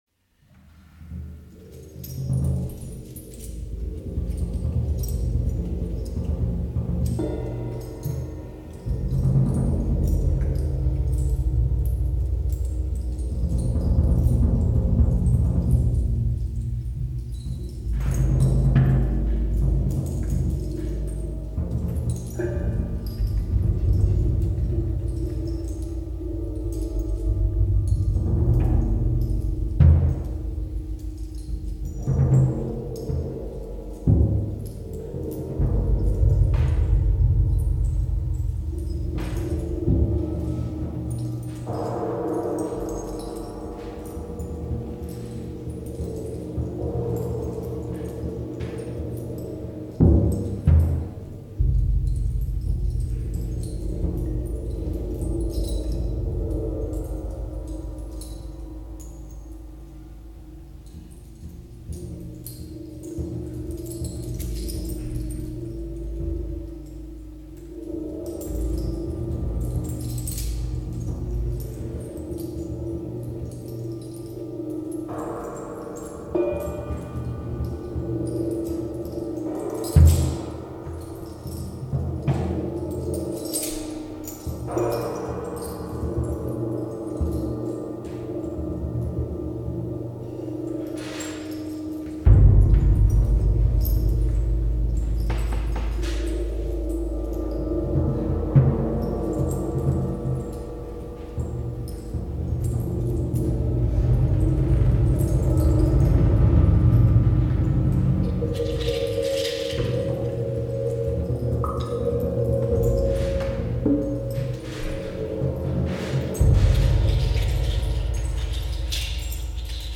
drumset / graphic scores / improvisation /